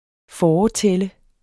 Udtale [ ˈfɒːɒˌtεlə ]